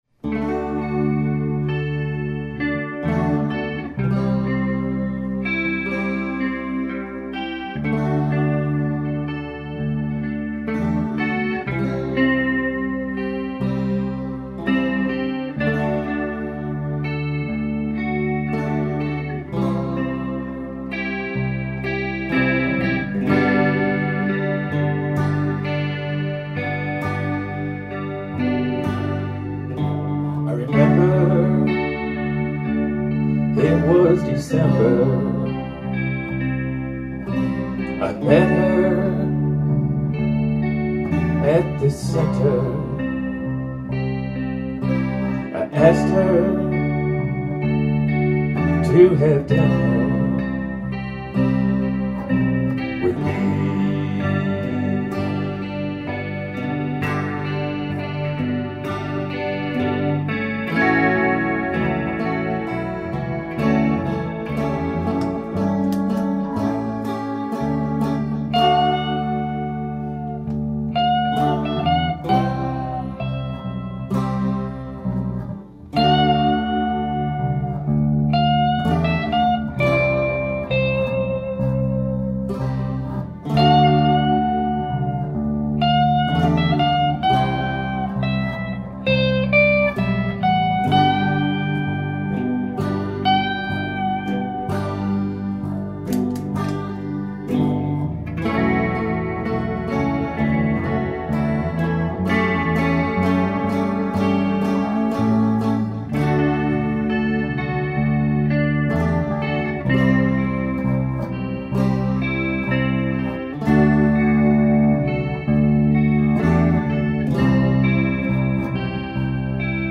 Electric guitar and vocals
Electric guitar
Bass